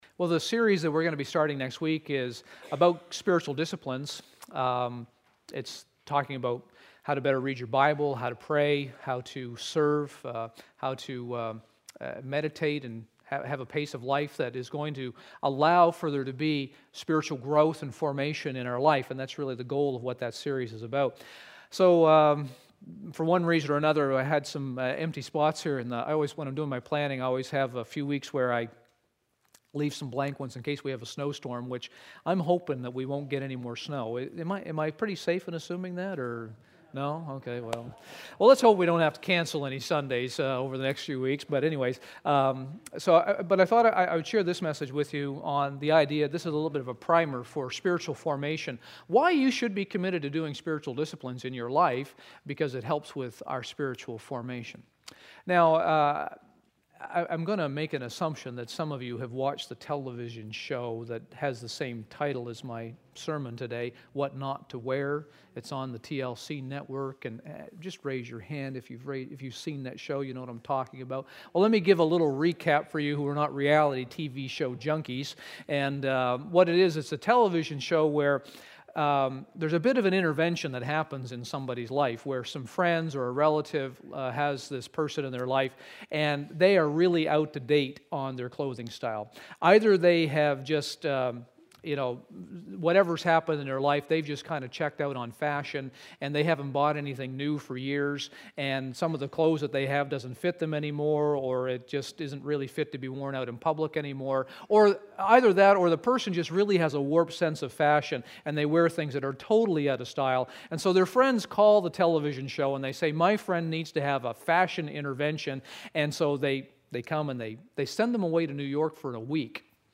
Sermons - Zion Baptist Church